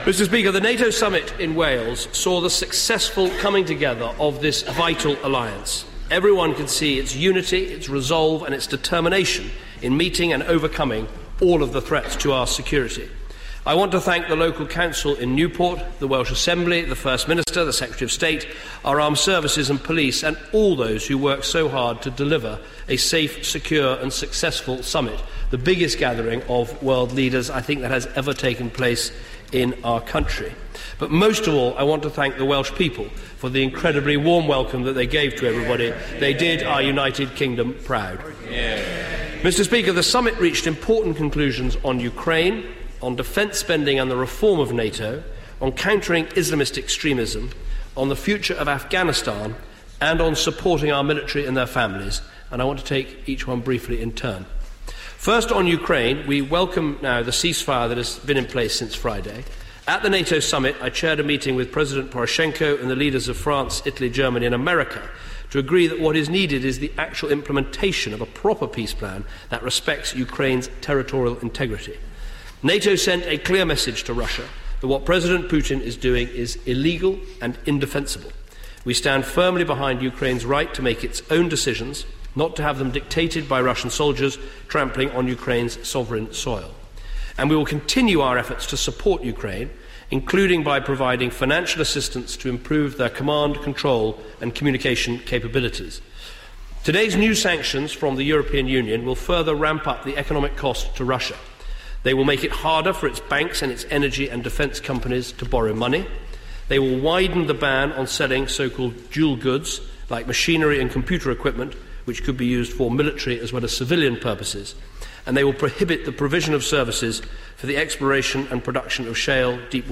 David Cameron's Nato statement
House of Commons, 8 September 2014